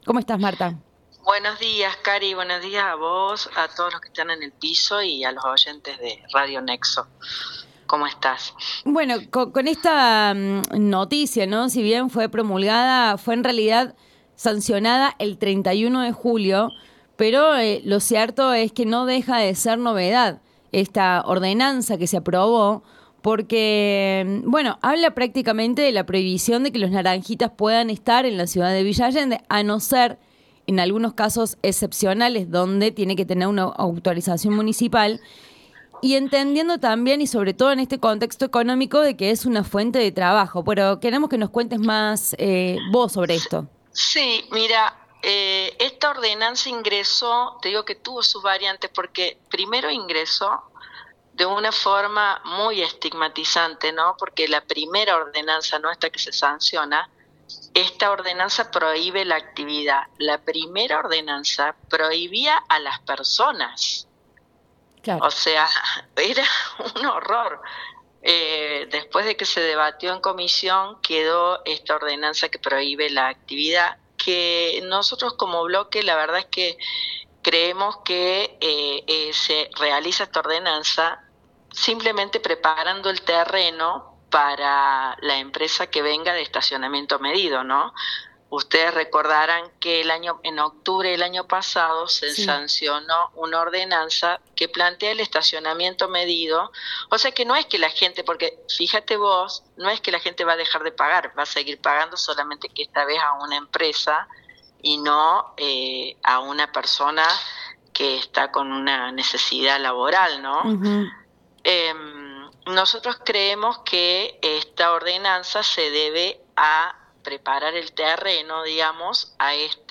ENTREVISTA A MARTA BANEGAS, CONCEJALA DE ELEGÍ VILLA ALLENDE